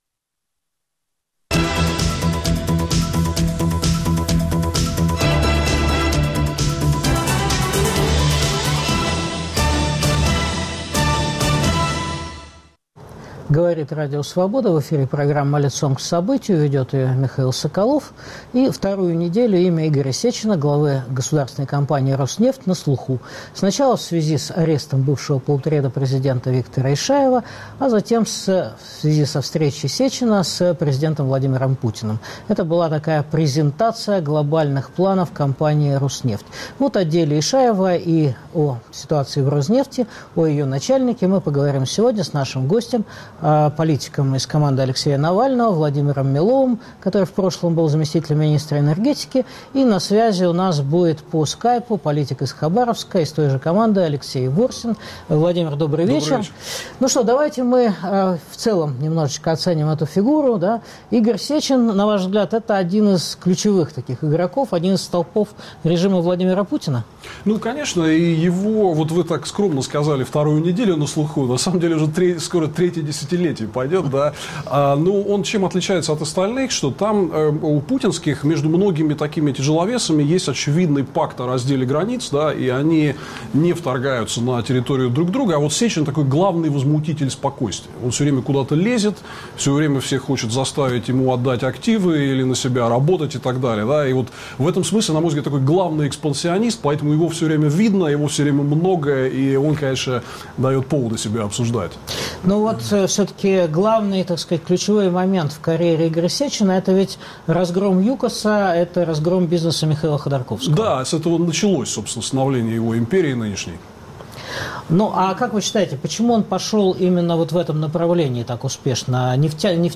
Участвует депутат Хабаровской городской думы Евгений Смолькин.